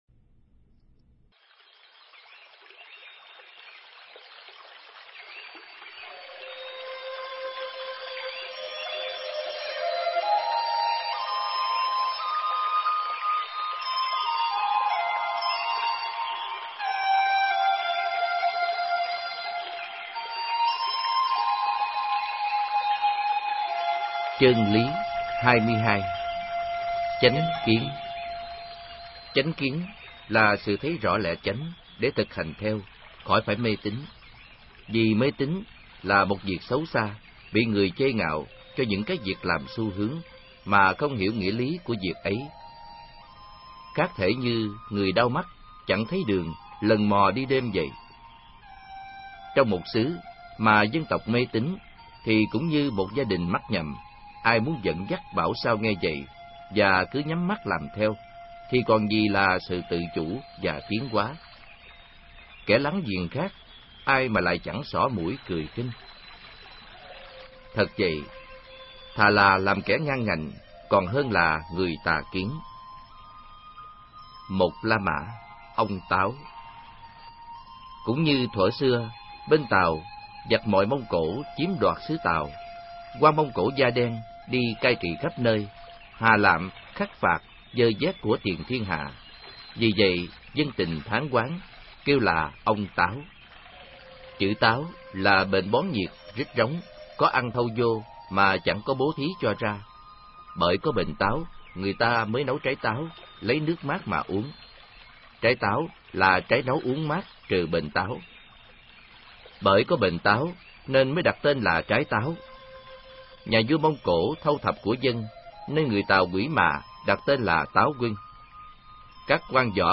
Nghe sách nói chương 22. Chánh Kiến